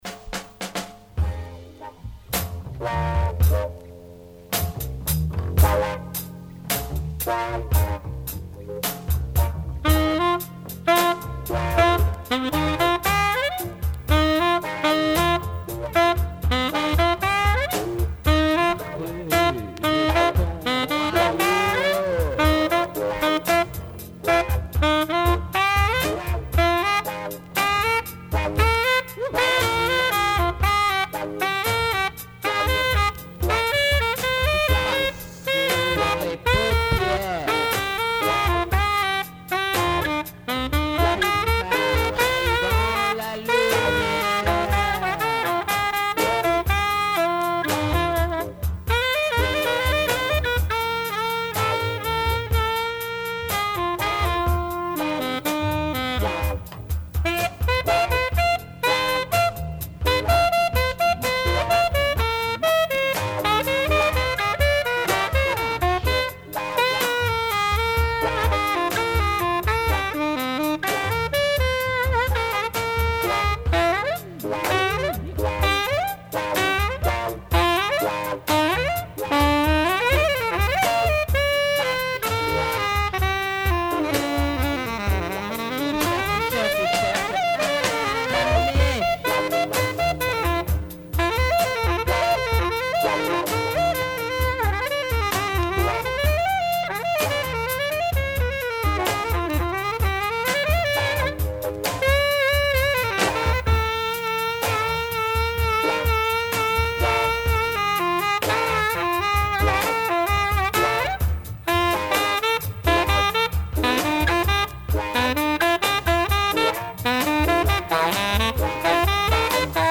Deep jazz on this rare French soundtrack.